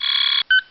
Polaris/sound/machines/rig/rigstarted.ogg at 948d43afecadc272b215ec2e8c46f30a901b5c18
rigstarted.ogg